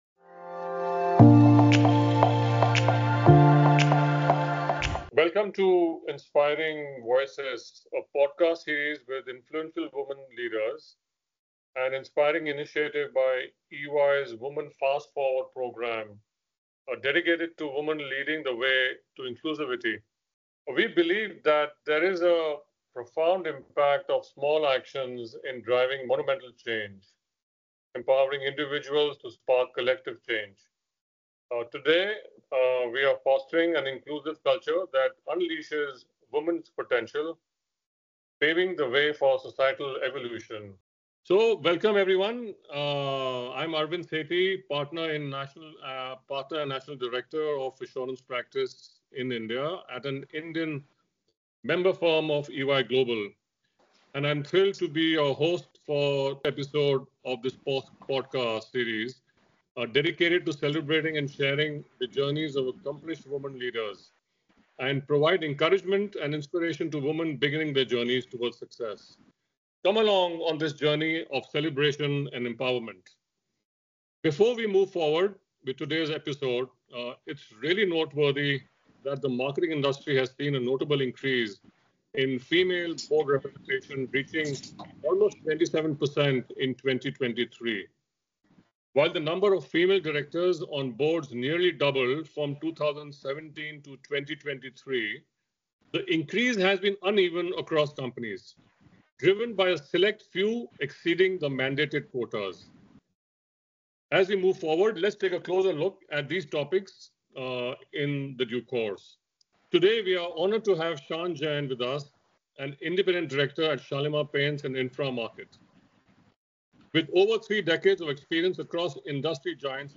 Leading with purpose: A conversation